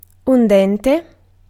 Ääntäminen
France: IPA: [yn dɑ̃]